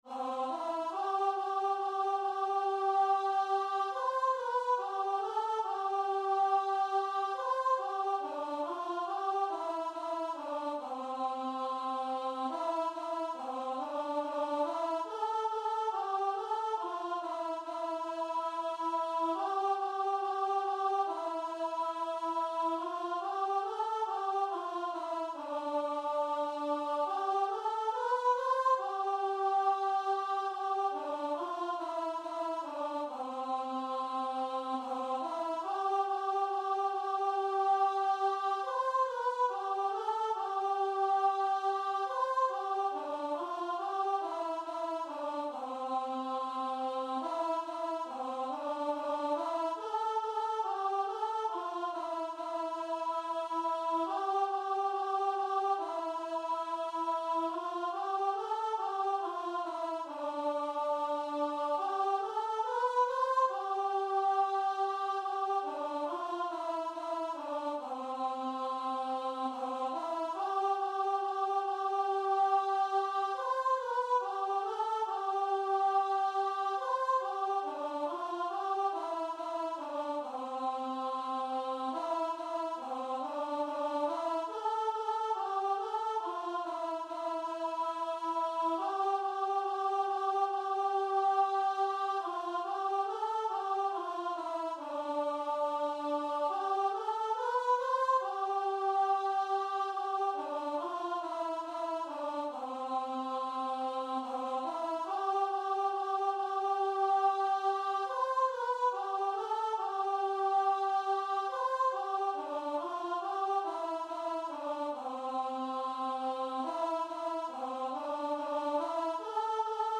Classical Trad. Attende Domine (Gregorian Chant) Voice version
Traditional Music of unknown author.
C major (Sounding Pitch) (View more C major Music for Voice )
C5-C6
4/4 (View more 4/4 Music)
Voice  (View more Easy Voice Music)
Christian (View more Christian Voice Music)